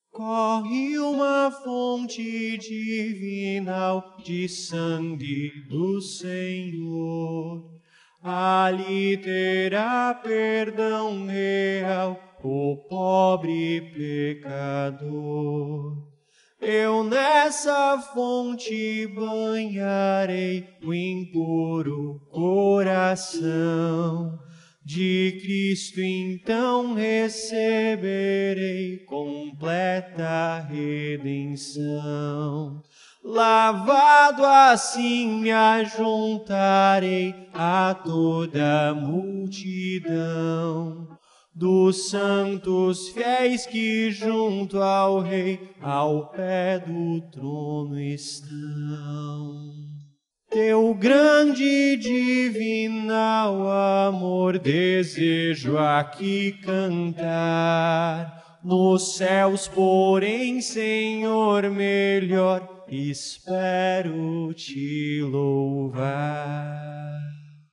Cântico: Corre uma fonte divinal
Para aprendizagem: Separei a voz dos instrumentos.